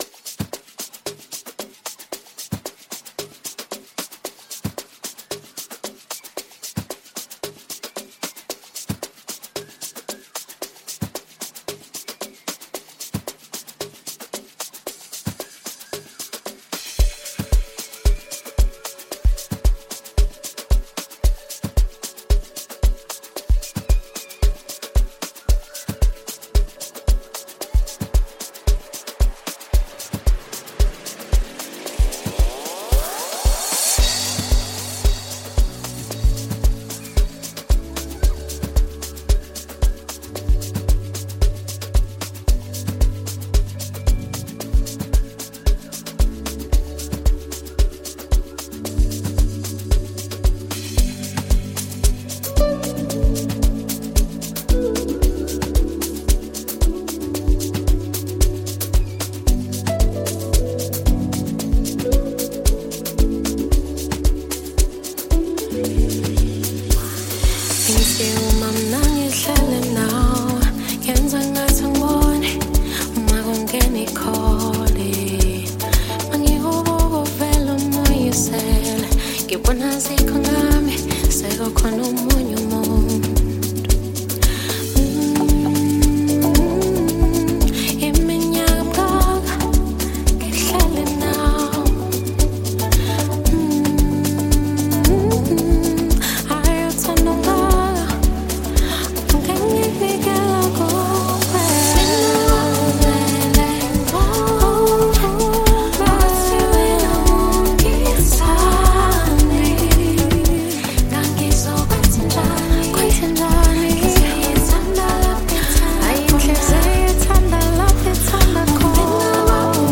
mixing smooth Afrobeat rhythms with heartfelt emotions